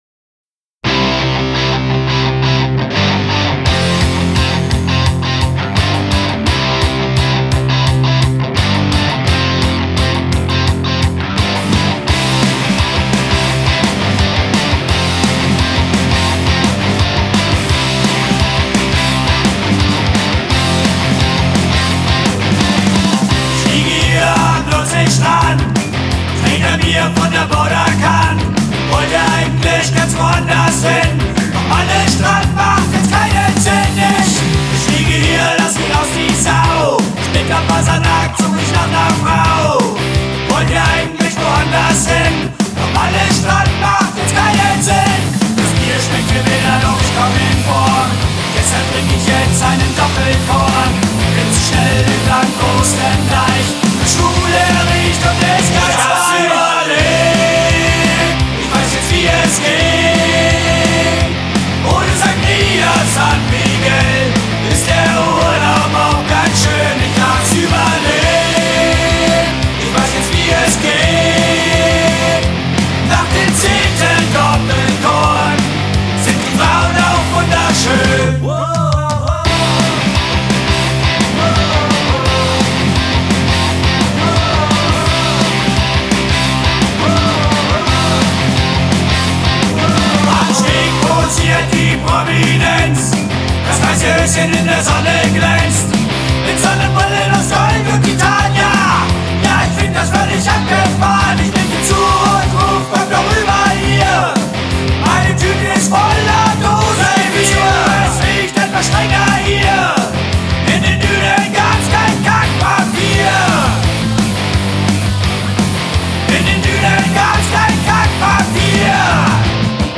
unkorrekte Partymusik